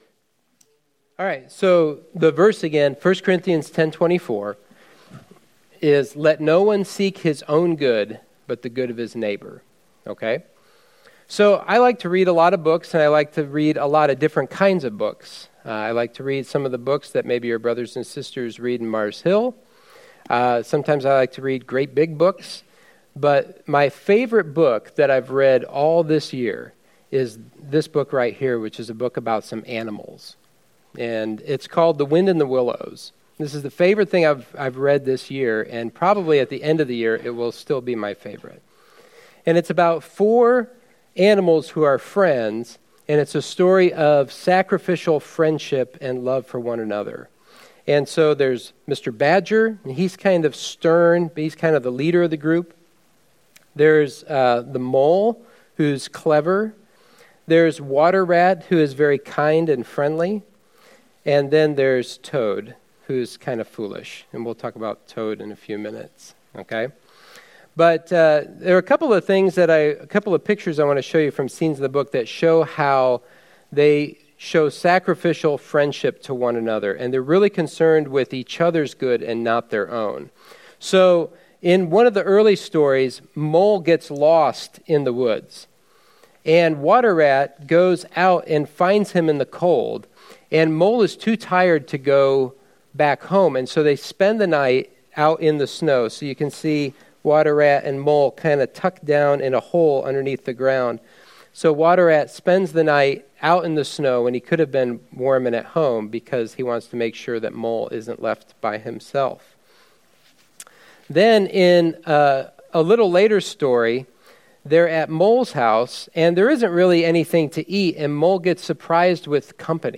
Sermon 4/20: The Good of Your Neighbor